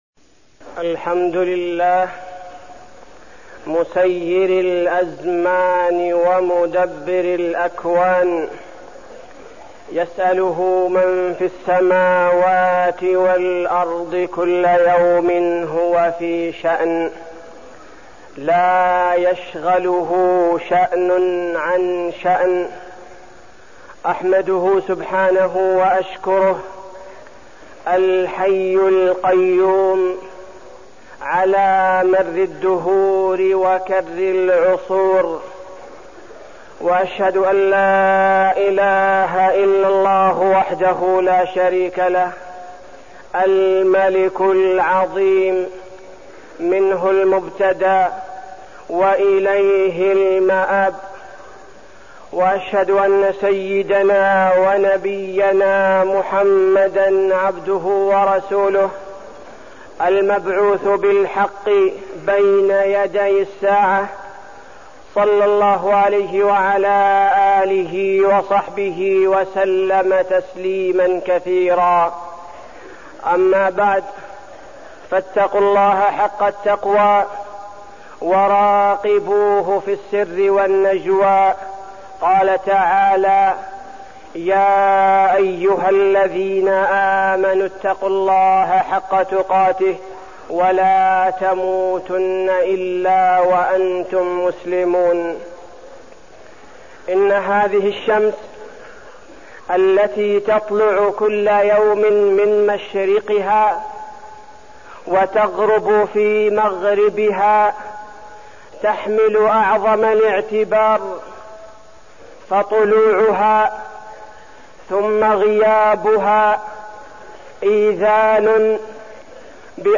تاريخ النشر ٢٥ ذو الحجة ١٤١٧ هـ المكان: المسجد النبوي الشيخ: فضيلة الشيخ عبدالباري الثبيتي فضيلة الشيخ عبدالباري الثبيتي استقبال عام جديد ومحاسبة النفس